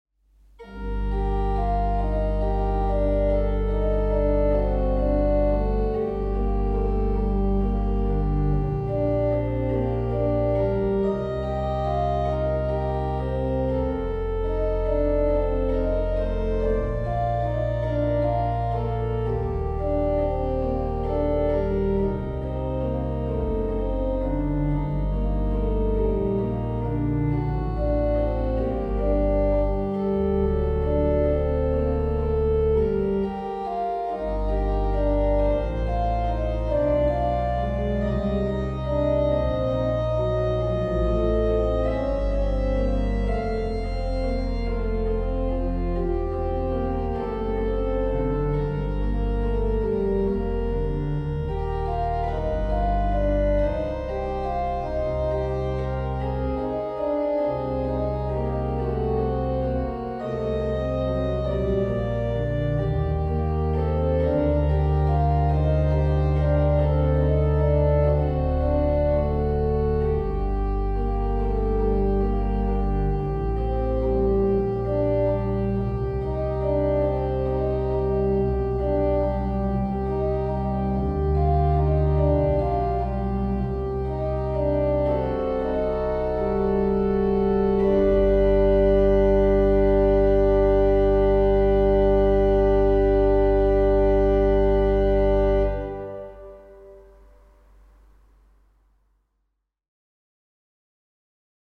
Joseph Gabriel Rheinberger, Nr.1 Andante aus 10 Trios für Orgel op.49, eingespielt an der Ahlborn-Orgel zu St. Peter & Paul Markkleeberg im Herbst 2021: